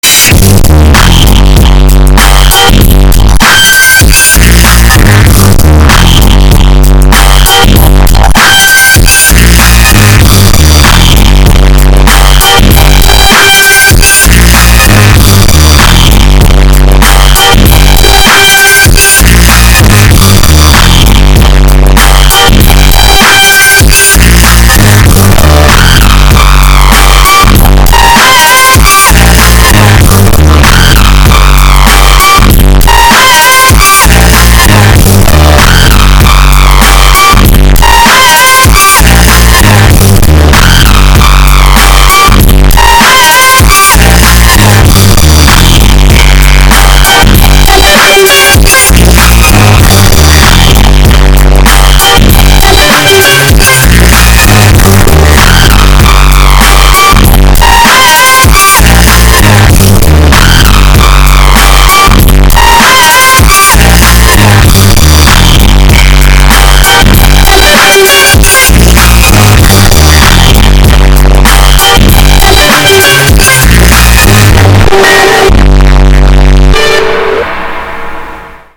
• Качество: 288, Stereo
мощные басы